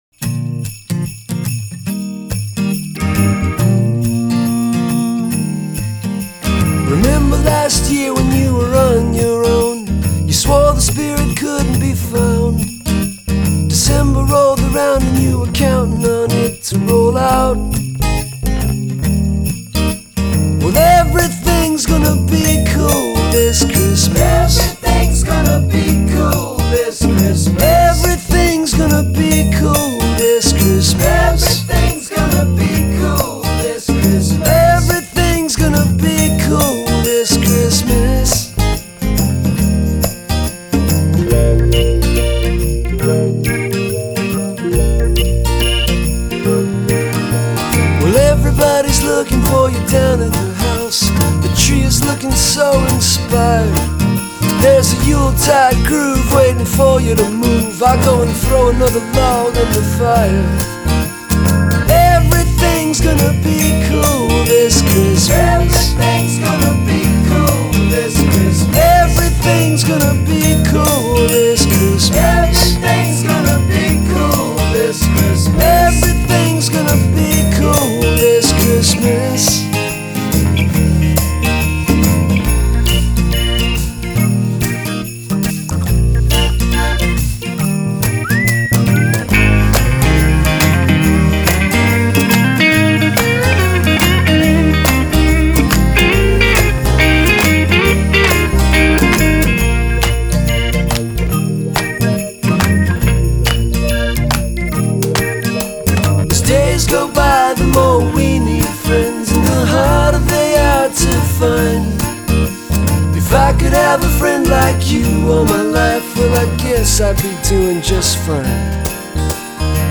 more crafted poprock